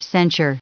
added pronounciation and merriam webster audio
142_censure.ogg